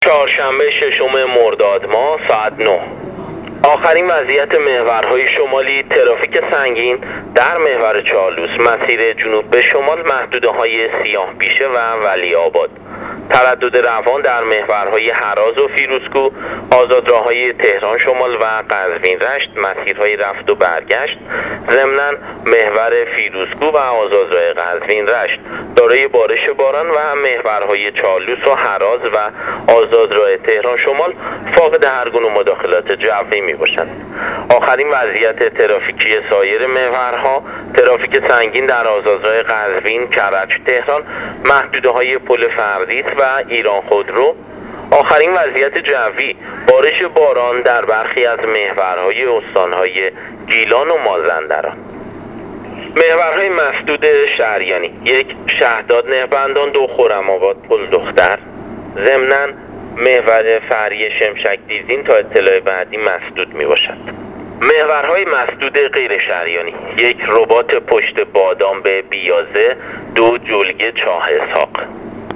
گزارش رادیو اینترنتی از آخرین وضعیت ترافیکی جاده‌ها تا ساعت۹ ششم مرداد